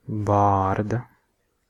Ääntäminen
Ääntäminen France: IPA: [baʁb] Haettu sana löytyi näillä lähdekielillä: ranska Käännös Ääninäyte 1. bārda {f} Suku: f .